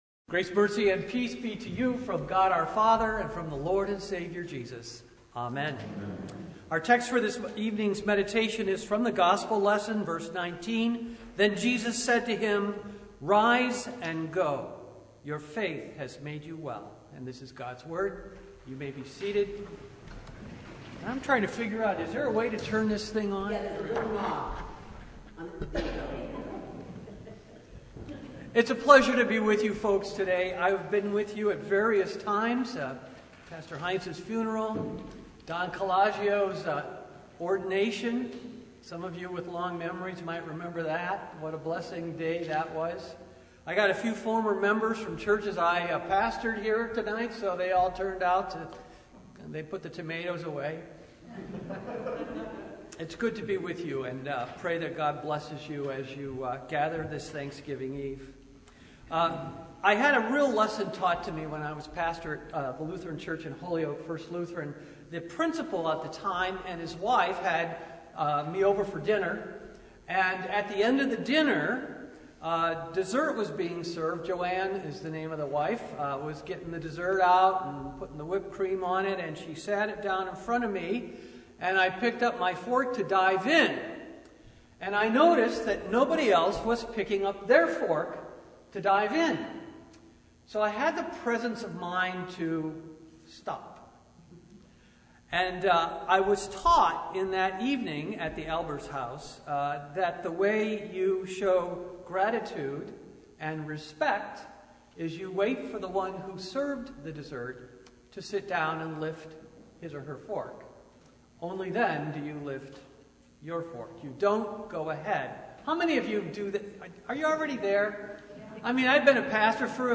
Sermon from Thanksgiving Eve (2024)
Preacher: Visiting Pastor Passage: Luke 17:11-19 Service Type